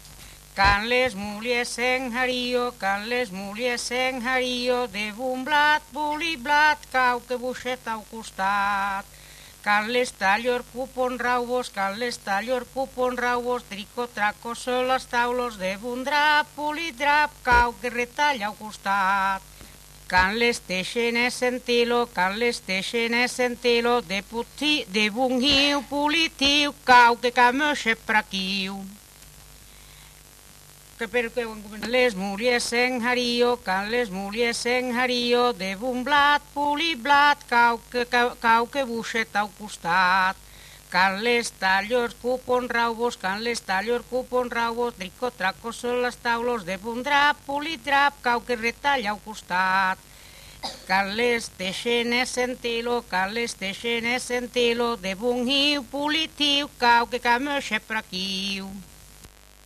Valse (fredonné